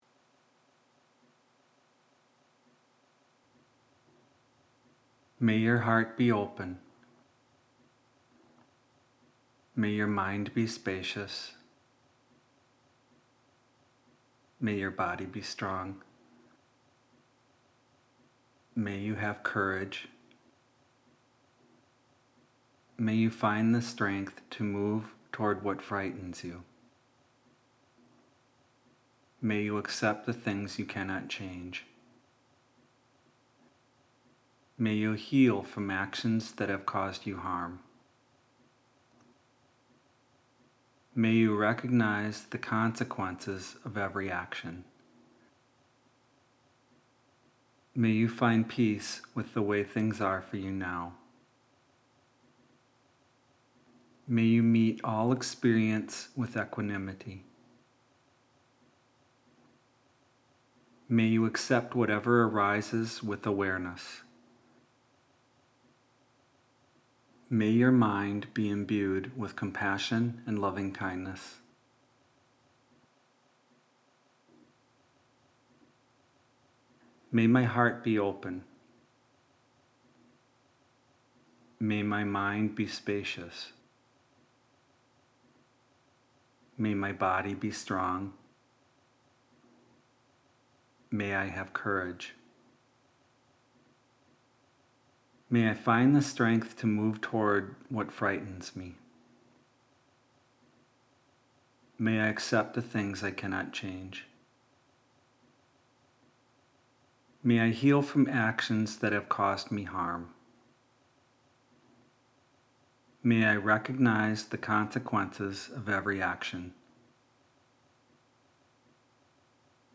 Spoken by a range of local practitioners, young and old. Please note: there are moments of silence at the beginning of each track and before and after each person speaks.